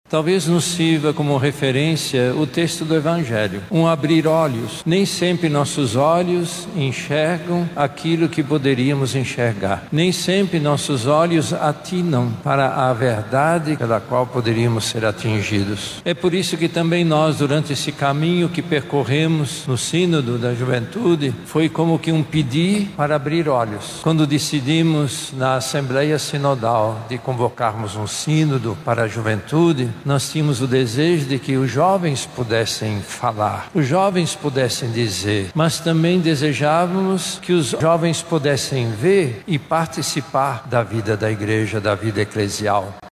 Na abertura da Assembleia, o Cardeal da Amazônia e Arcebispo Metropolitano de Manaus, Dom Leonardo Steiner, destacou a importância da escuta, do espaço de fala e da atuação dos jovens, na Igreja.